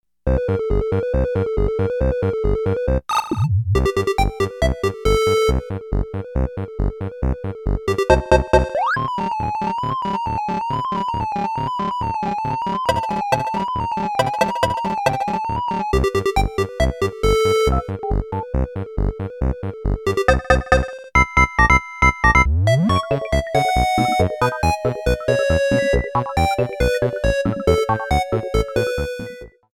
Trimmed, added fadeout